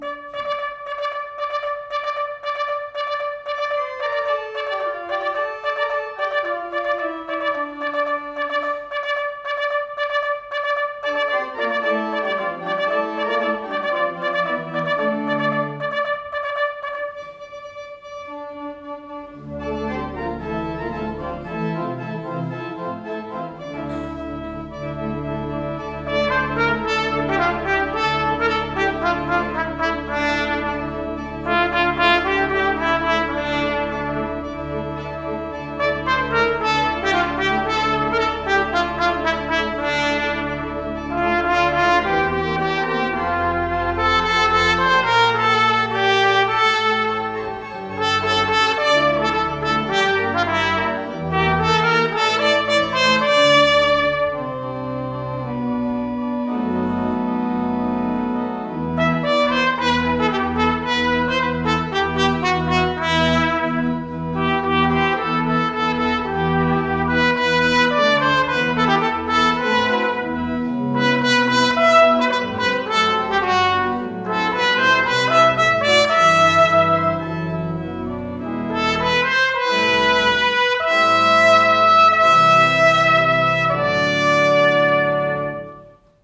concert de Noël – église Ste Croix de Lorry
La légende de St Nicolas (trad. lorrain)  –  Trompette et Orgue